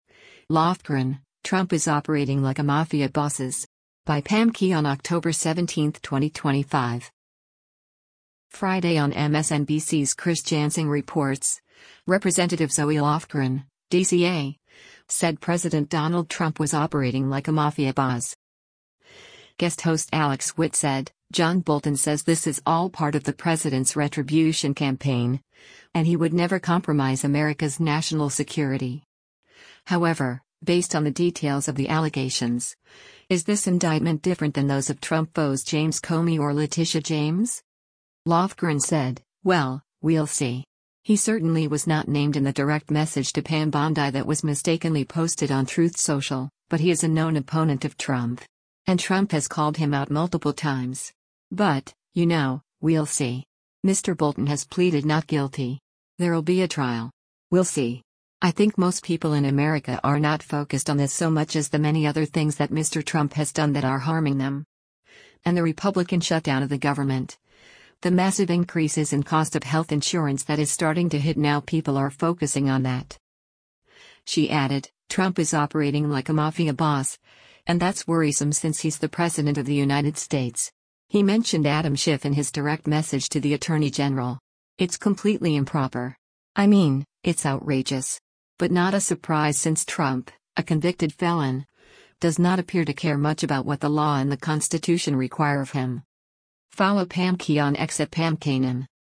Friday on MSNBC’s “Chris Jansing Reports,” Rep. Zoe Lofgren (D-CA) said President Donald Trump was “operating like a mafia boss.”